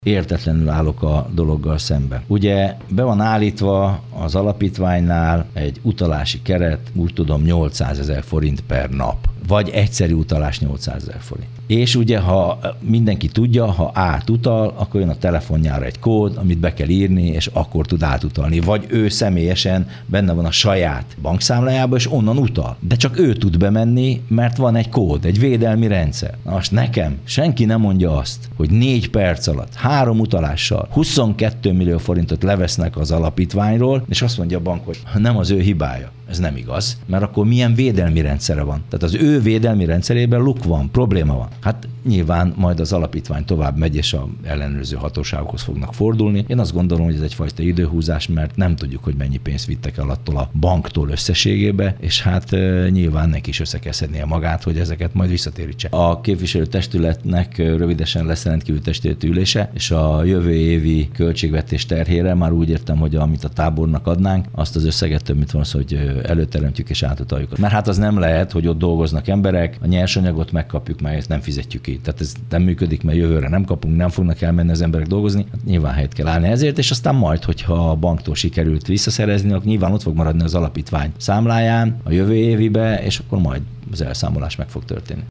Értetlenül állnak az önkormányzatok a révfülöpi üdülőtábor alapítványi számlájának kifosztása előtt - mondta Pápai Mihály, az egyik fenntartó, Gyál város polgármestere.